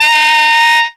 OB GRUNT.wav